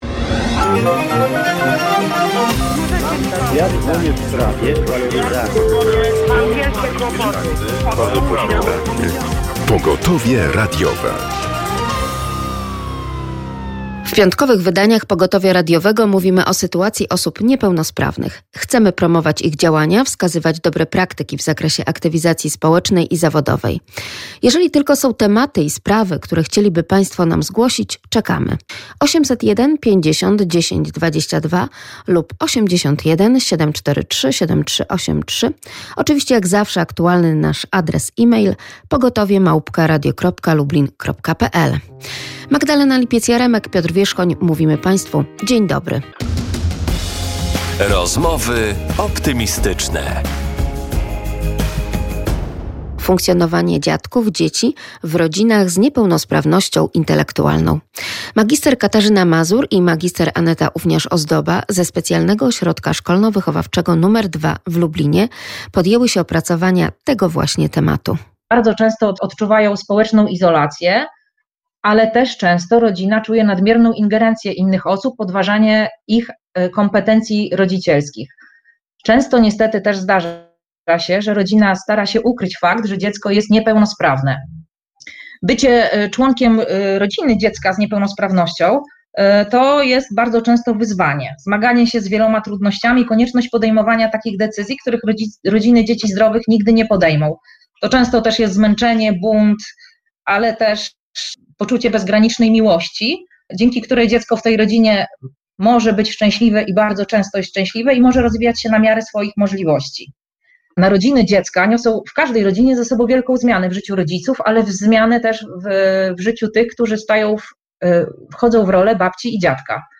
Będzie to dyskusja na temat dostępności miejsc historycznych z uwzględnieniem zróżnicowanych potrzeb odbiorców oraz wartości zabytkowych miejsca, a także rozmowy o możliwościach współdziałania w zakresie adaptacji zabytków dla osób ze szczególnymi potrzebami.